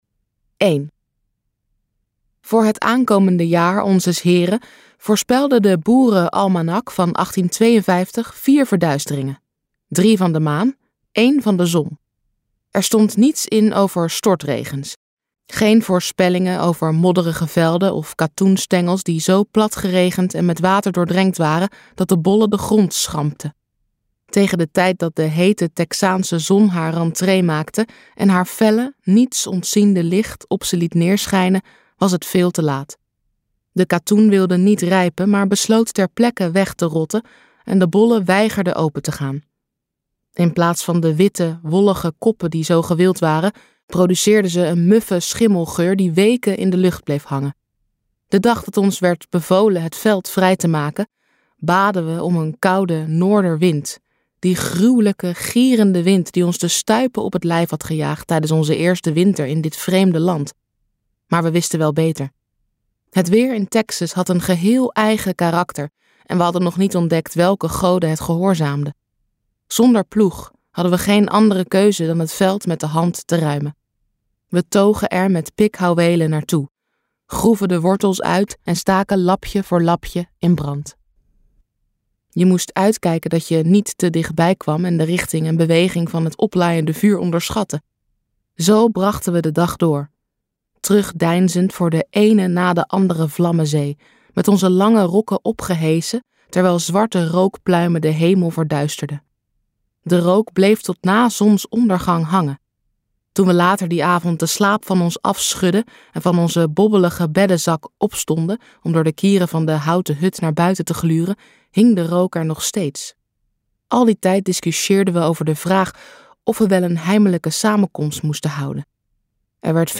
Ambo|Anthos uitgevers - Waar we gaan is nacht luisterboek